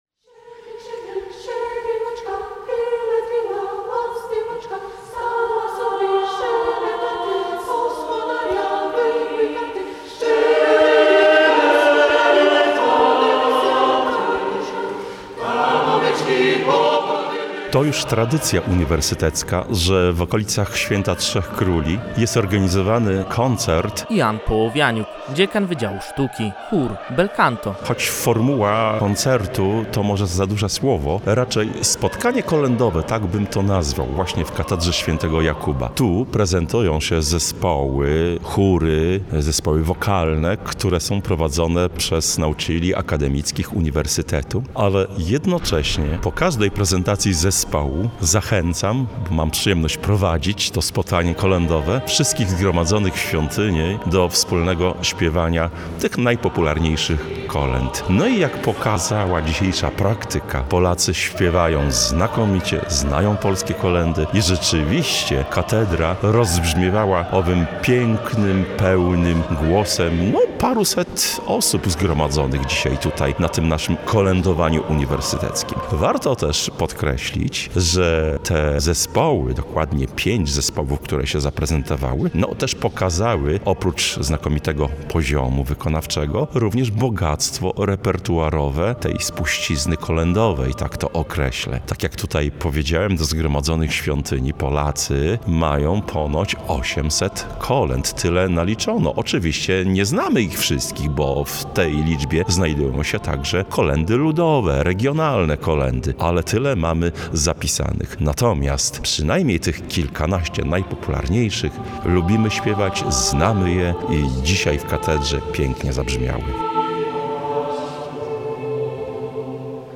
W murach olsztyńskiej katedry w niedzielę 11 stycznia ponownie zabrzmiały najpiękniejsze kolędy i pastorałki.
Zespół Pieśni i Tańca „Kortowo” w tym roku w Uniwersyteckim Koncercie „Śpiewajmy Kolędy” zaprezentował trzy kolędy i pastorałki.